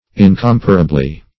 -- In*com"pa*ra*ble*ness, n. -- In*com"pa*ra*bly, adv.